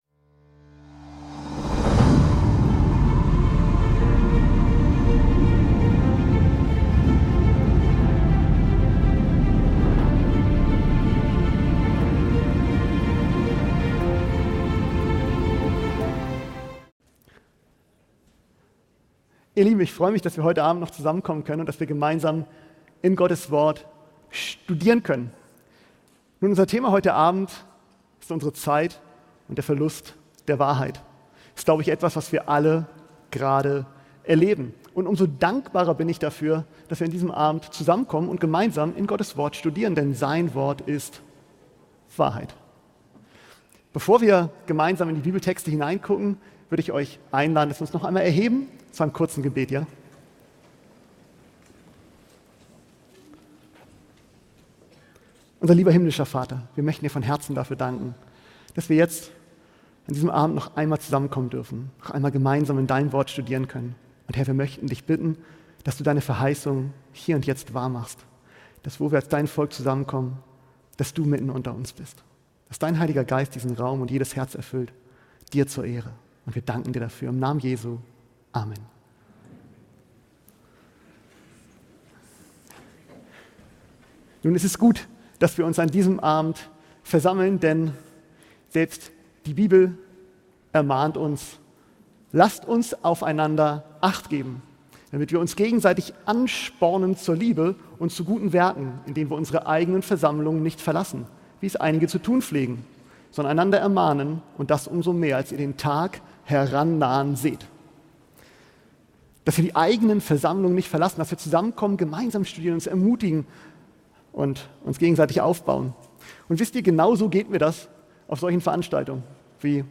Der Vortrag beleuchtet den Kampf zwischen Wahrheit und Lüge in der heutigen Zeit und betont die Wichtigkeit, in Übereinstimmung mit Gottes Wort zu leben. Glaubensfragen, persönliche Entscheidungen und das Streben nach Wahrheit stehen im Zentrum.